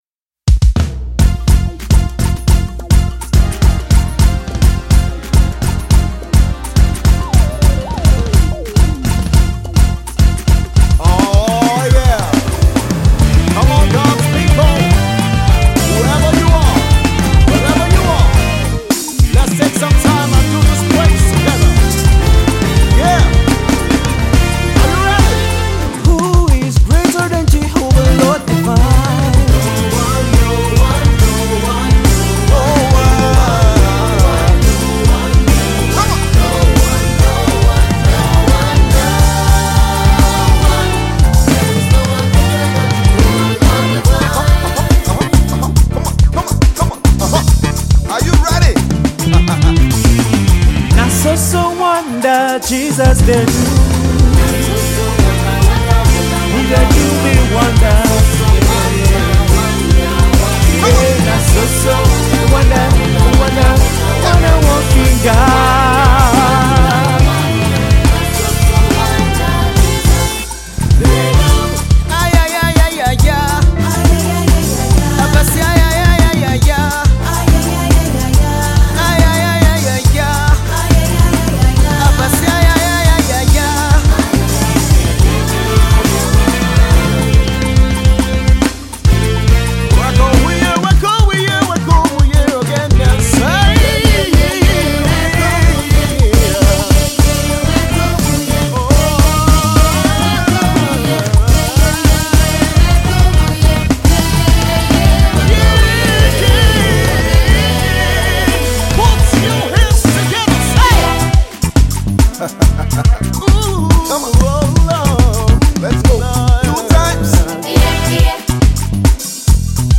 Contemporary Nigerian gospel group
an addictive African praise medley of popular Nigerian songs
Synth programming & Keyboard